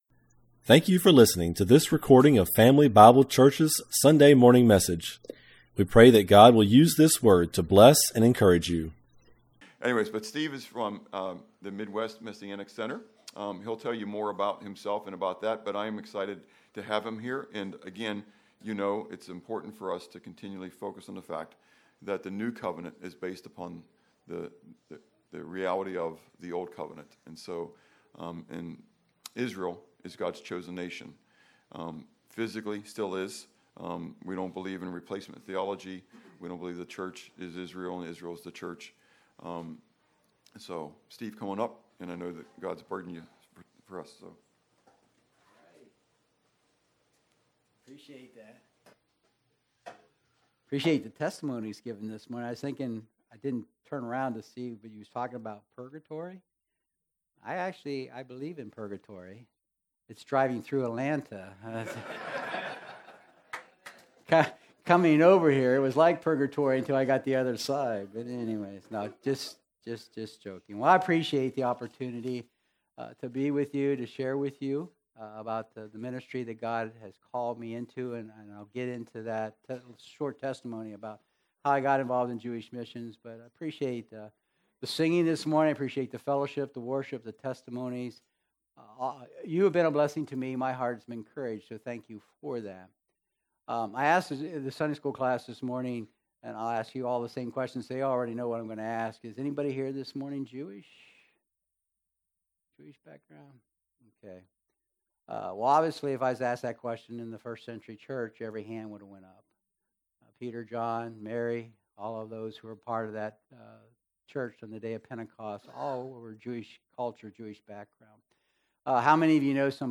Family Bible Church Message From 28 September 2025
Sermon